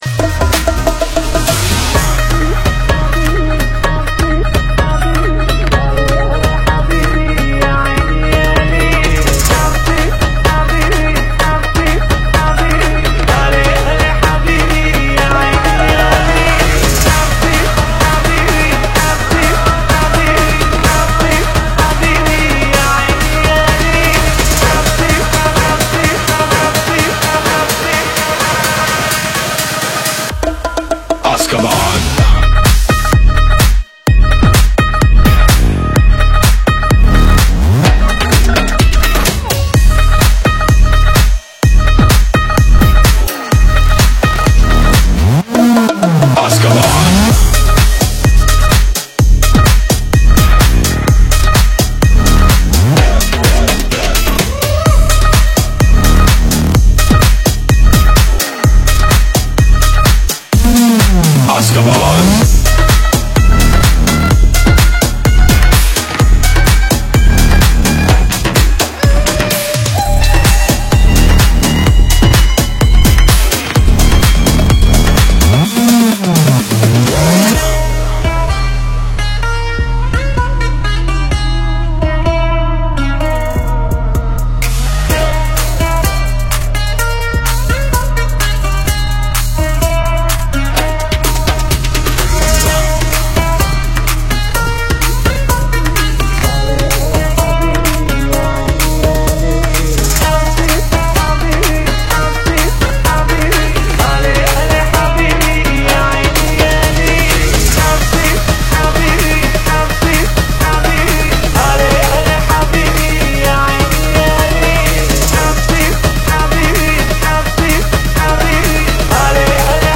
muitos sucessos em versões eletrizantes